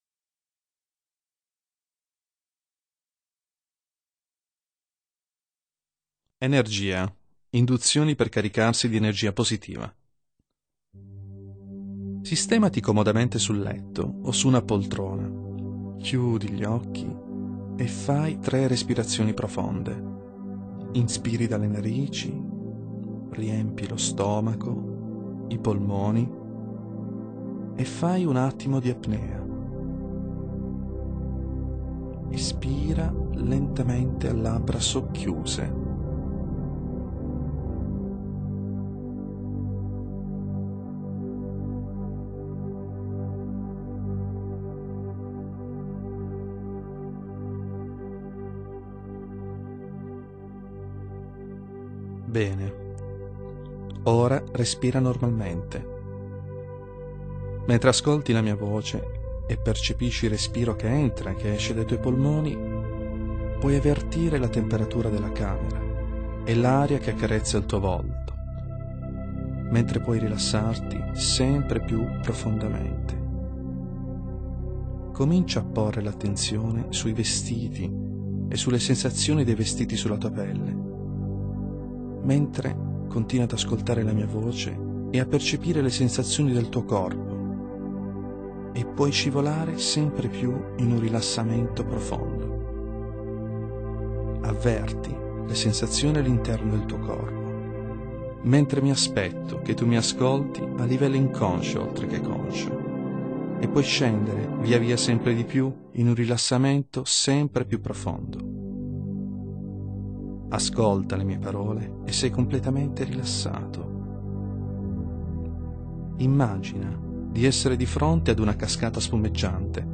Meditazione di energia positiva – Guida audio mp3